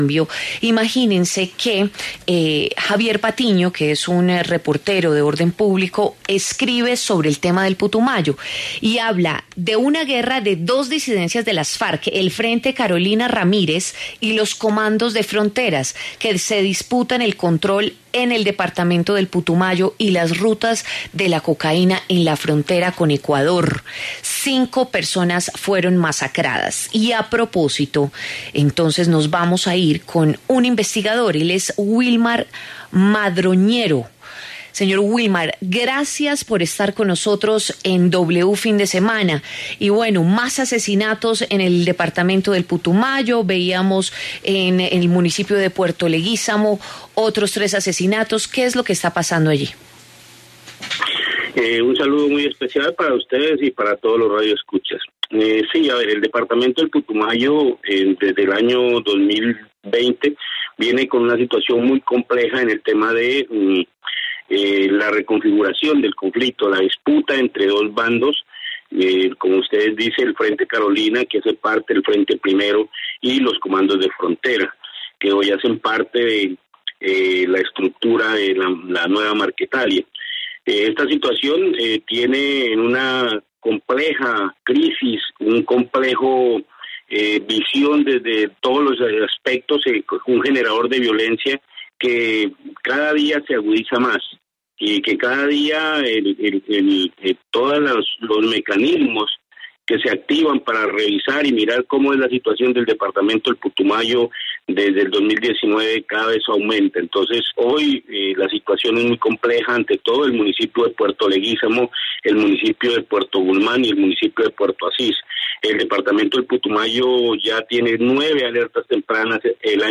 En entrevista con W Fin de Semana